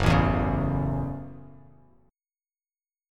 F#m7 chord